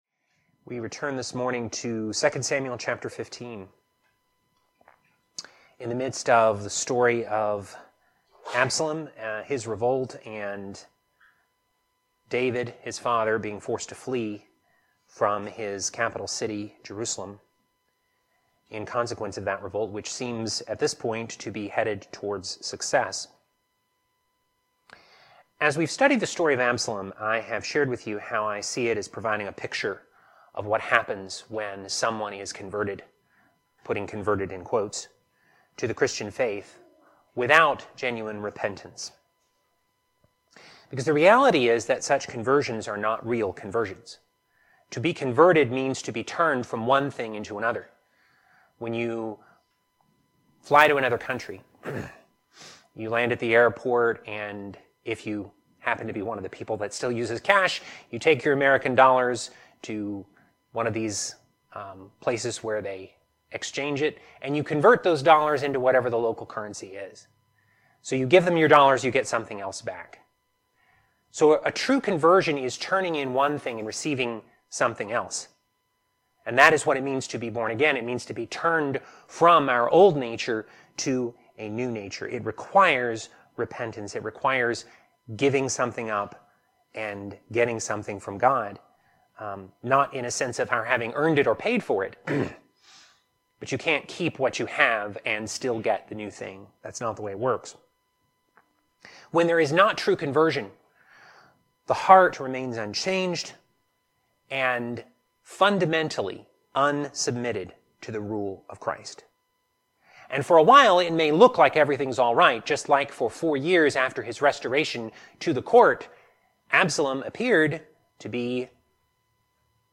Teaching For December 15, 2024